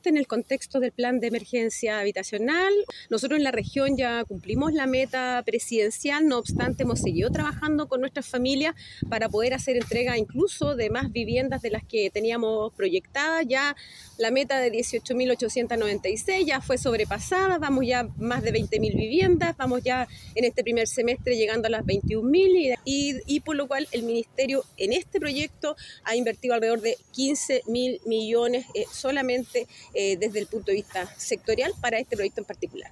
La seremi de Vivienda y Urbanismo del Bío Bío, Claudia Toledo, se refirió al éxito que ha tenido el Plan de Emergencia Habitacional en el que se enmarca el proyecto.